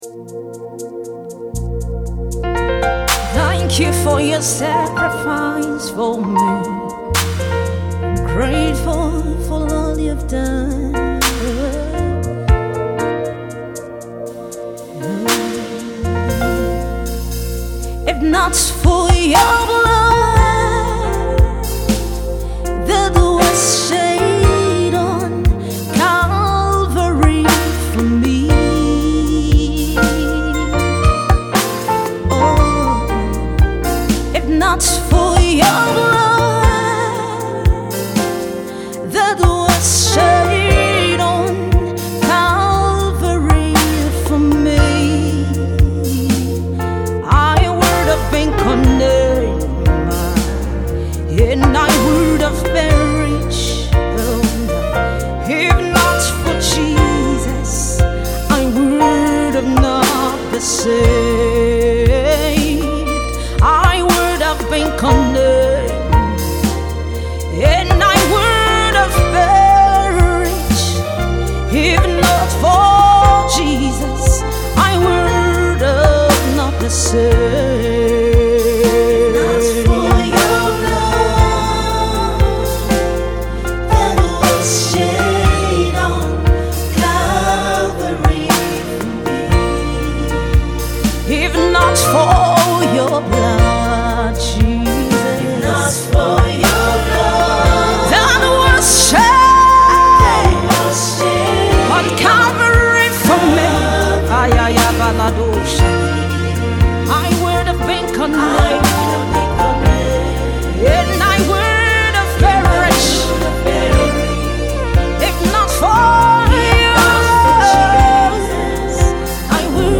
Nigerian gospel music minister and exquisite songwriter